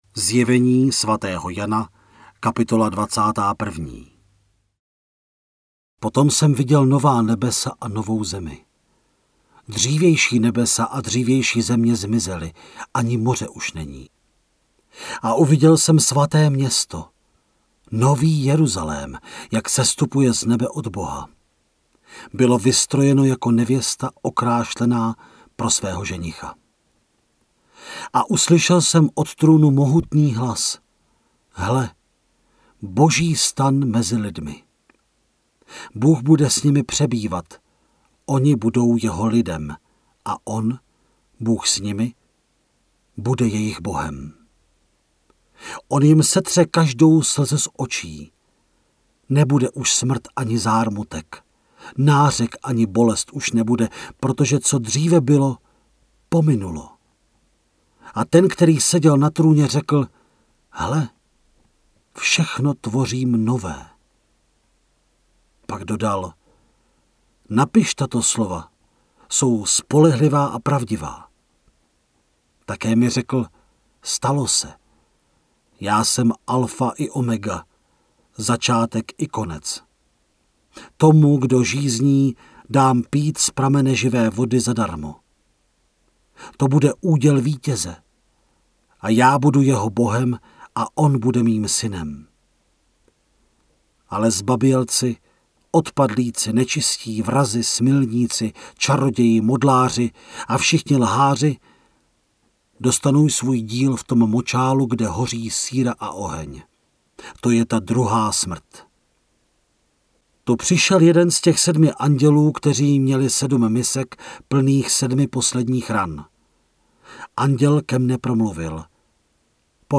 Načtená kniha je rozdělená na 22 částí, které odpovídají dělení kapitol.
Stahujte celé zde (75MB):  ZJEVENÍ SVATÉHO JANA – audiokniha, Studio Vox 2018